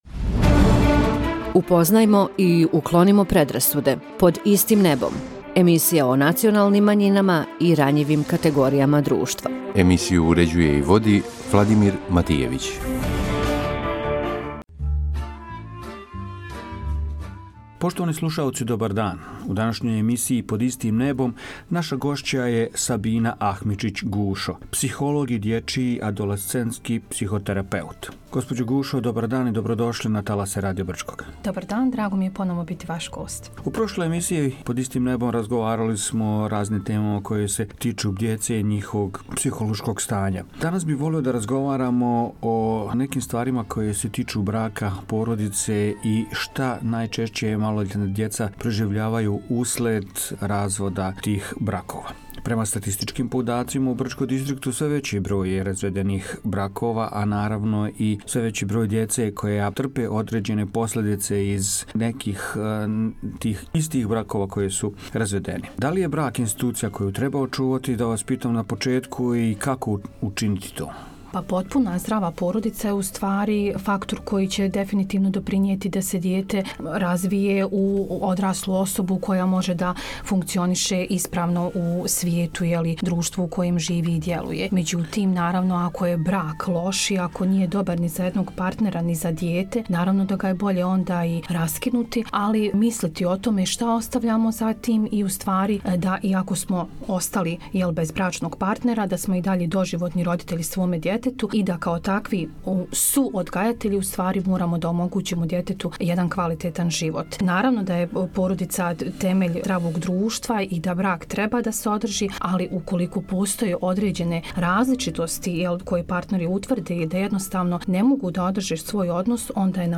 Гост емисије “Под истим небом” – психолог